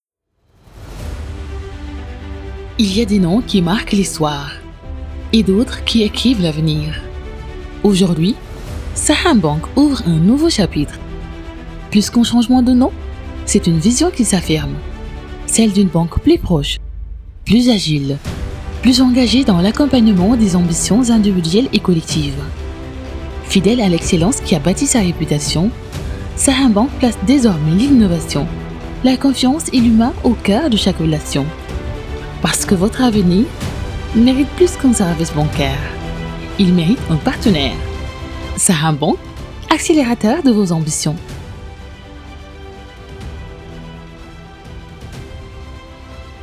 • Audio Voix Off
publicité audio commandée par Saham Bank